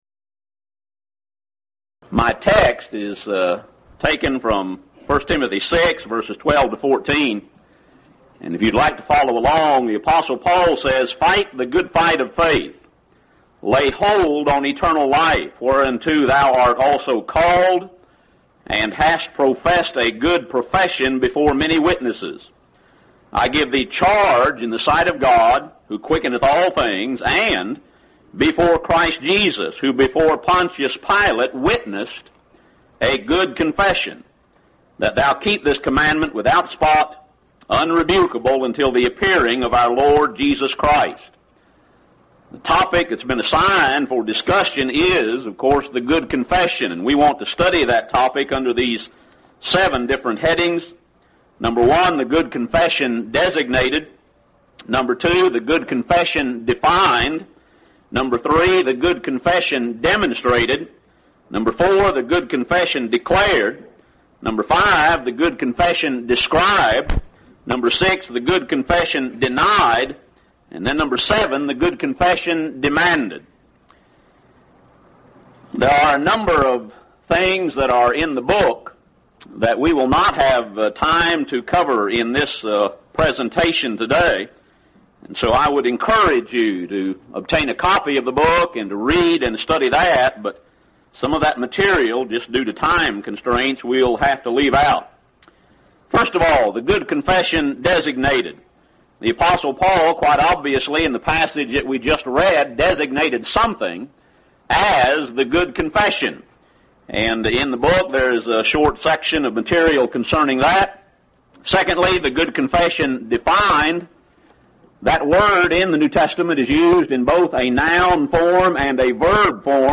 Event: 2nd Annual Lubbock Lectures